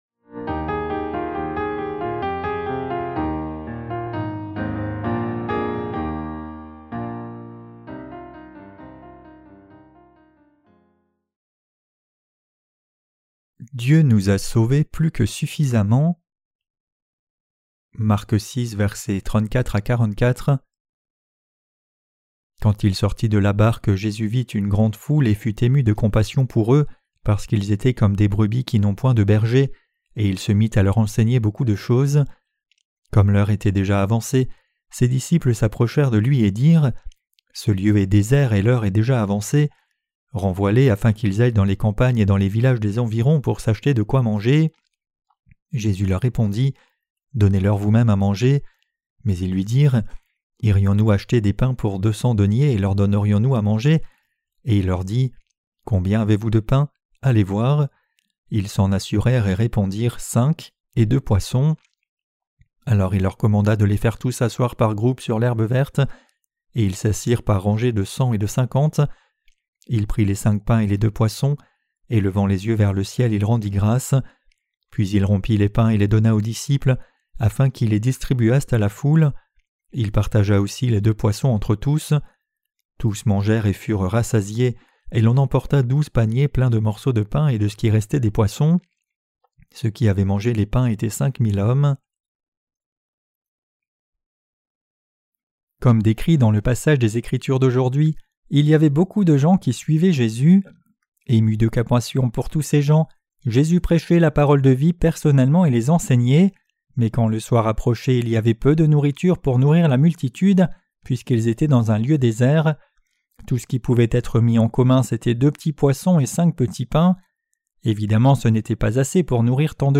Sermons sur l’Evangile de Marc (Ⅰ) - QUE DEVRIONS-NOUS NOUS EFFORCER DE CROIRE ET PRÊCHER? 15.